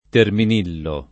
[ termin & llo ]